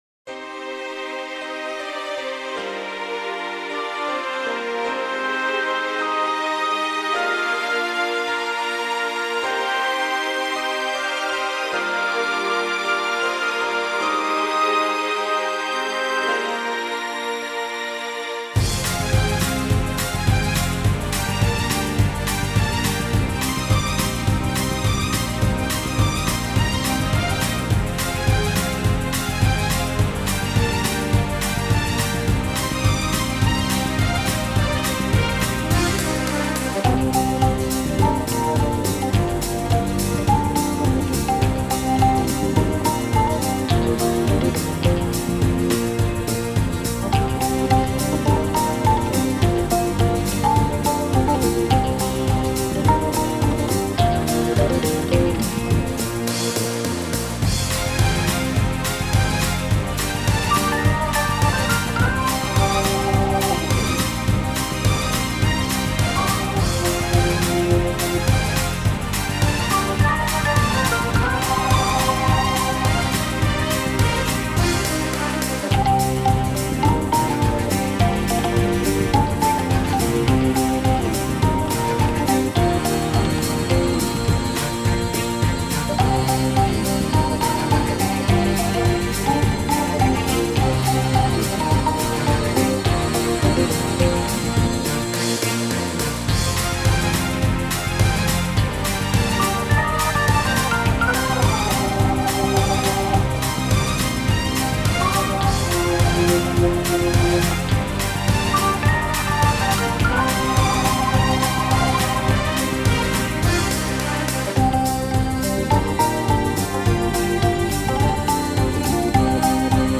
инструментальную версию